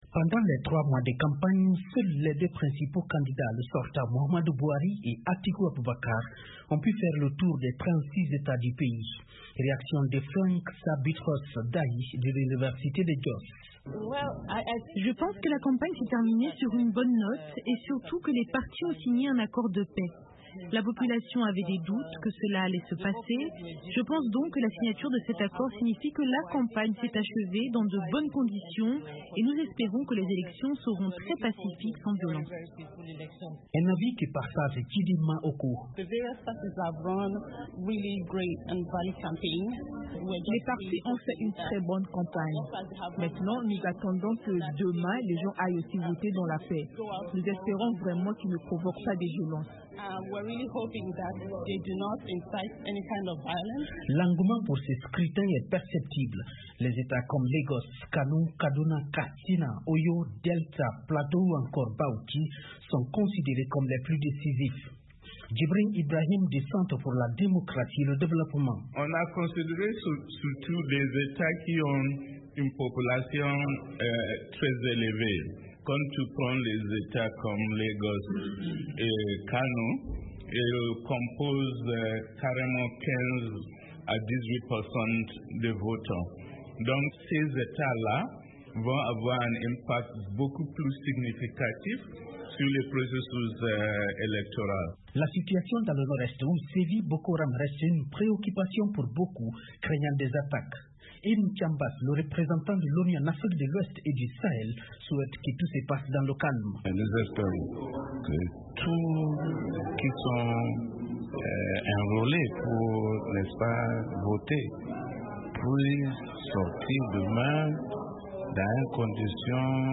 Les Nigérians se rendent aux urnes samedi pour des élections présidentielles et législatives. La campagne a pris fin jeudi et les candidats ont rejoint leurs états d’origine pour voter dans un scrutin présidentiel qui s’annonce très serré entre les deux principaux candidats. Le reportage